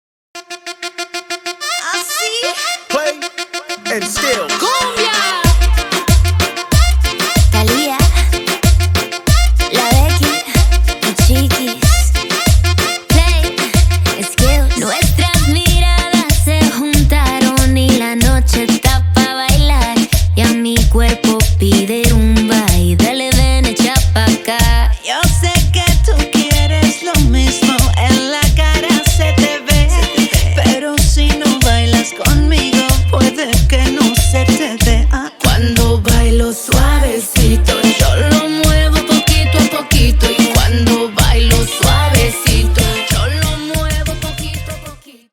Танцевальные # клубные # латинские